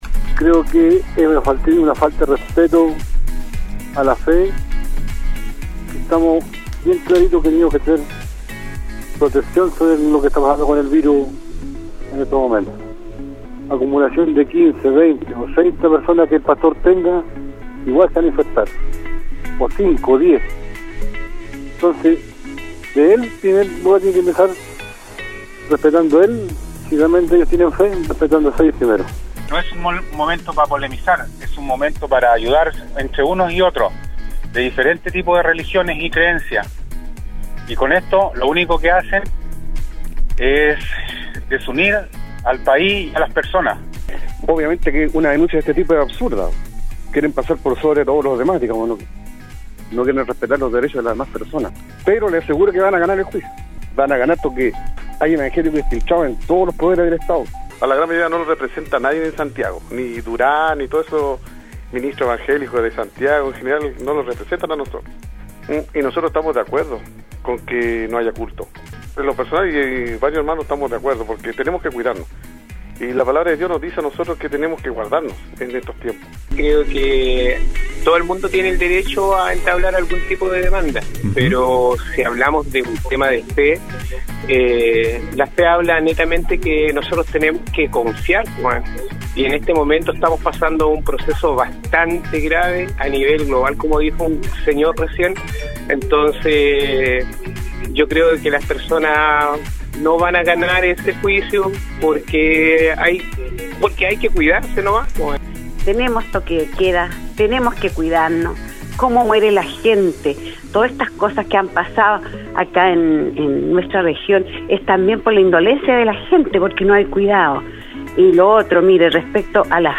Este miércoles, en el programa Al Día de Nostálgica se llevó a cabo un nuevo Foro de Opinión, donde le consultamos a nuestros auditores y auditoras, respecto de su parecer con la noticia, publicada por Bio Bio Chile, que consignada que Grupos evangélicos del Bío Bío presentarán una denuncia en contra del Estado ante la Comisión Interamericana de Derechos Humanos, acusando la violación a sus libertades religiosas en medio de la pandemia del coronavirus.